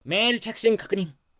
『斑(Madla Spots)』に置いてあるNewVoice，OldVoiceはフリー素材 & ボイスサンプルです。